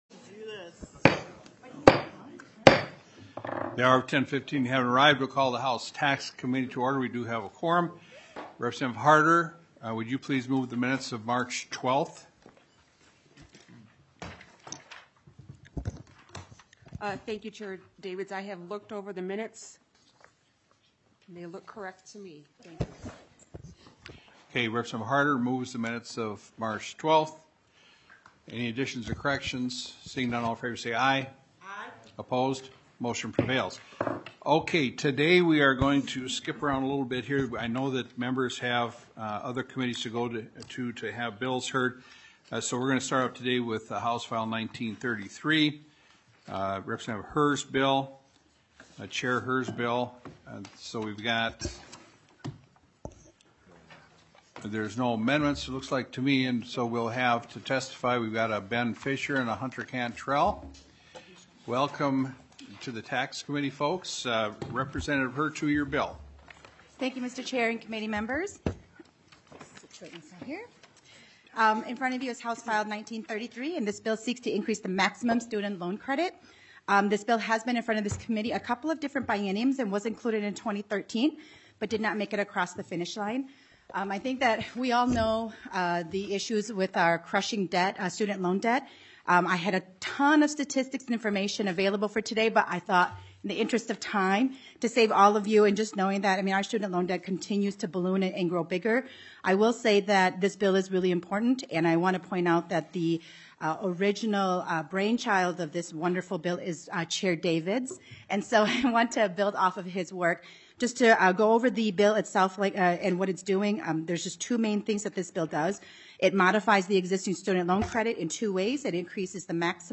Taxes FOURTEENTH MEETING - Minnesota House of Representatives